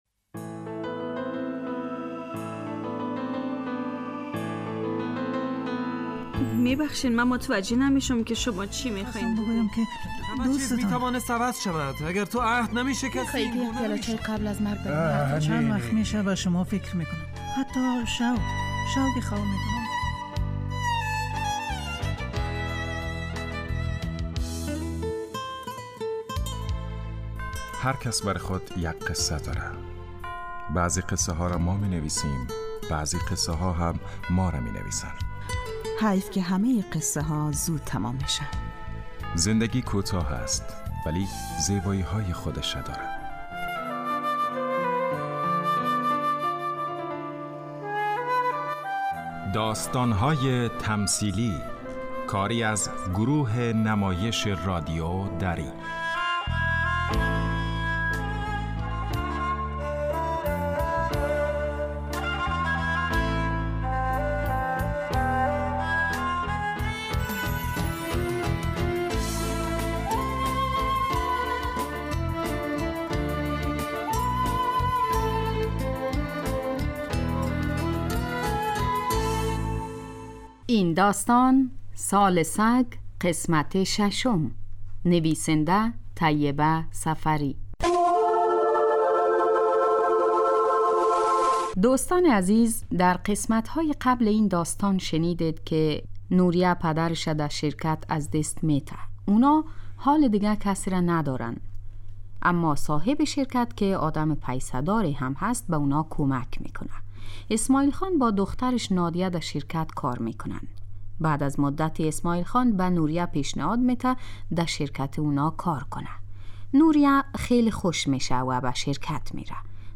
داستان تمثیلی / سال سگ